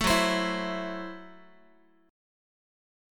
Gbm7b5 chord